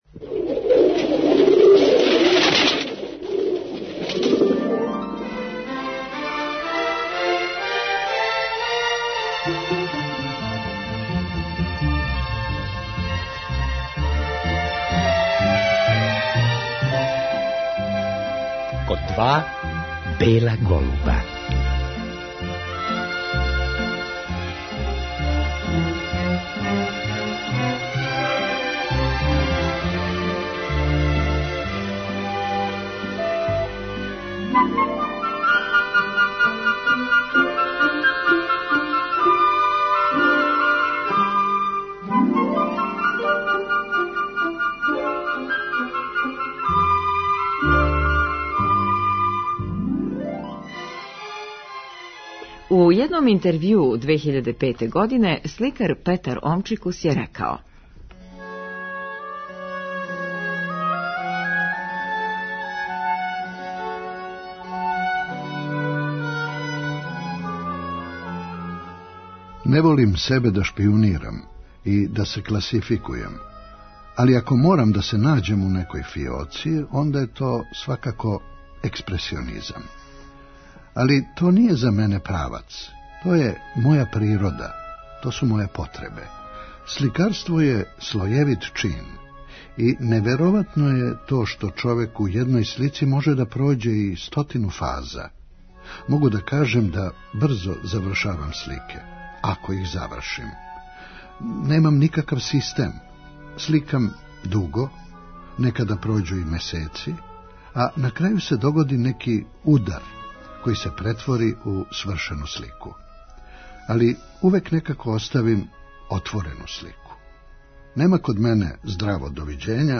У вечерашњој емисији ''Код два бела голуба'' слушаћемо сећања нашег чувеног сликара и академика Петра Омчикуса на одрастање и уметничко сазревање, на формирање Задарске групе, одлазак у Париз "на само пар месеци"... а чућемо и његова размишљања о сликарству.